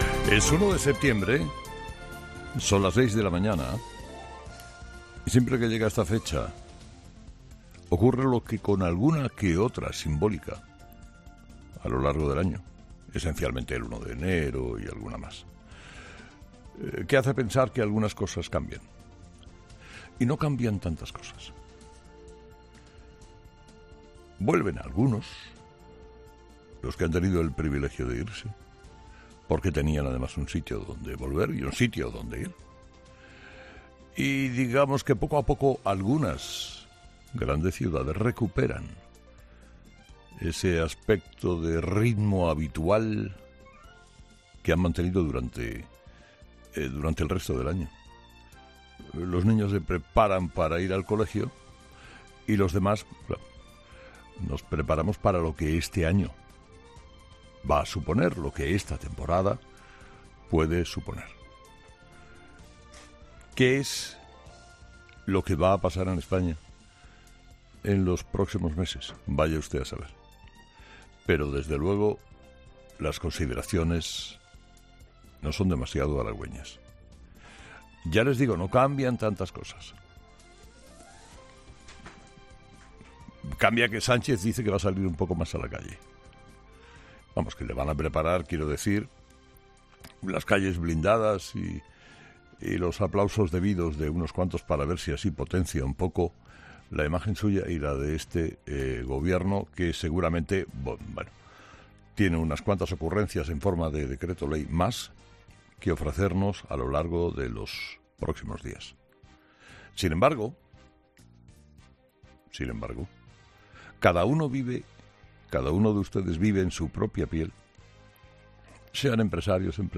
Carlos Herrera, director y presentador de 'Herrera en COPE', ha comenzado este jueves una nueva temporada al frente del programa líder en el prime time de la radio española.